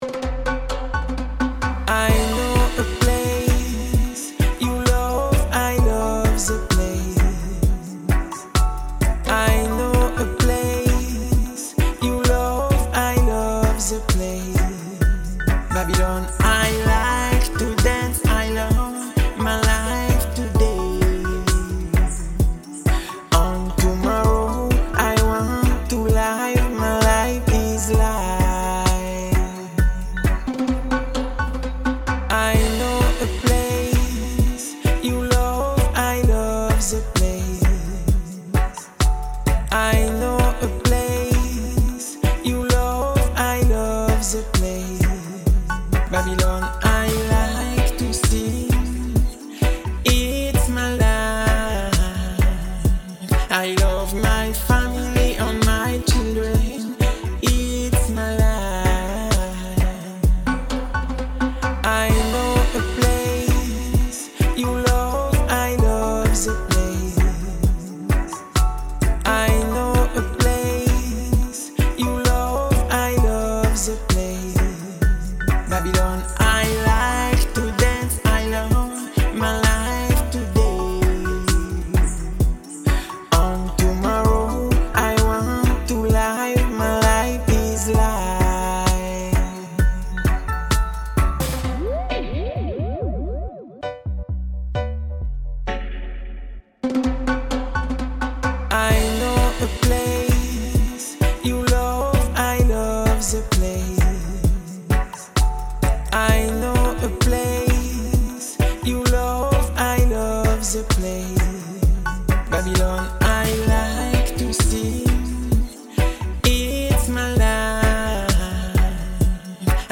reggae